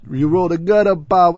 gutterball-3/Gutterball 3/Commentators/Louie/l_yourolledagutter.wav at 1f83e602871f1a638b2bd4eaf8b3ea58dd8393f7
l_yourolledagutter.wav